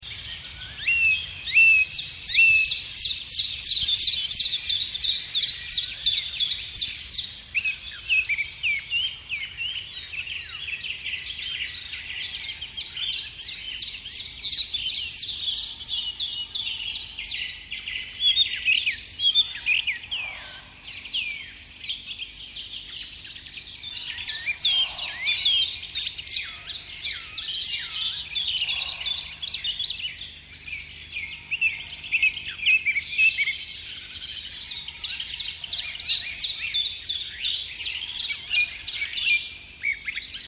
Звуки леса
Отличного качества, без посторонних шумов.
1123_zvuki-lesa.mp3